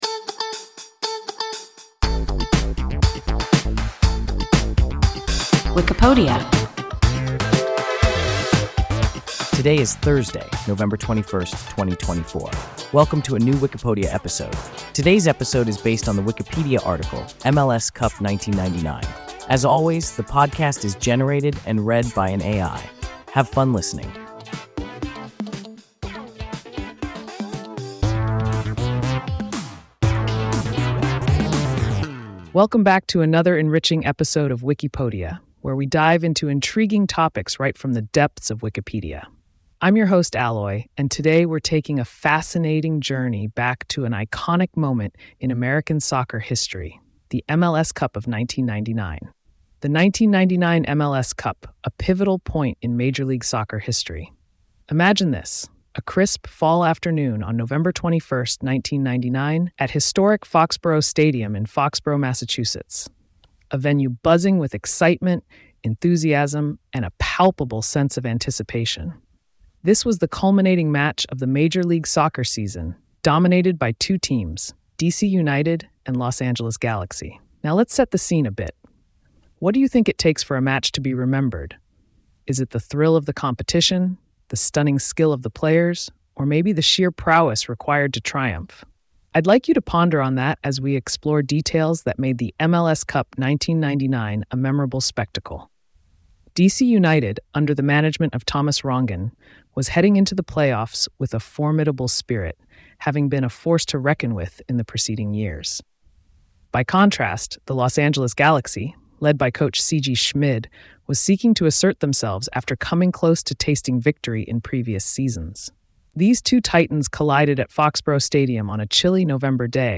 MLS Cup 1999 – WIKIPODIA – ein KI Podcast